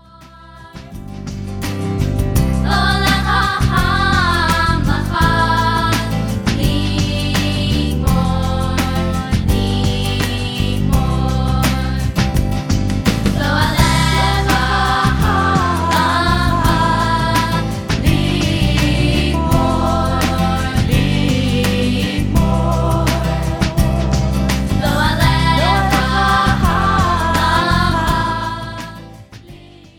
Folk music for the Jewish soul.